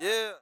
Tm8_Chant72.wav